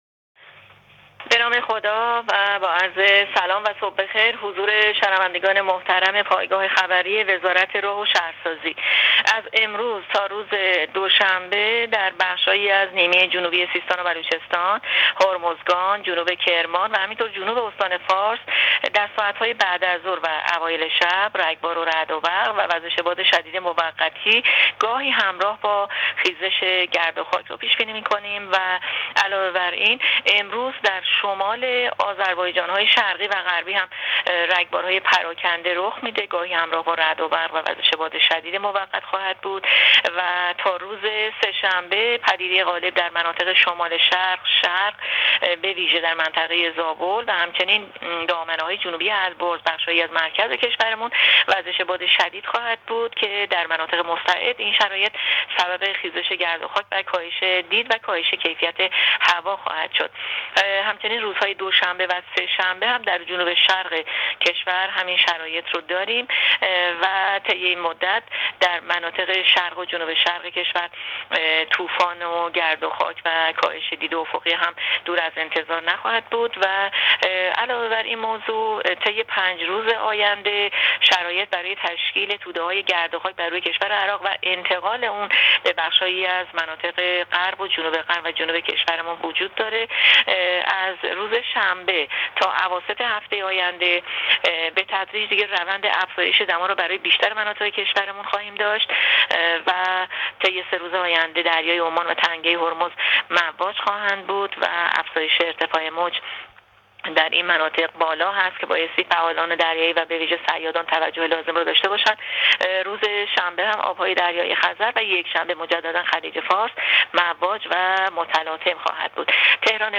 کارشناس سازمان هواشناسی در گفت‌وگو با رادیو اینترنتی پایگاه خبری وزارت راه‌ و شهرسازی، آخرین وضعیت آب‌وهوای کشور را اعلام کرد.
گزارش رادیو اینترنتی پایگاه خبری از آخرین وضعیت آب‌وهوای هفدهم تیرماه؛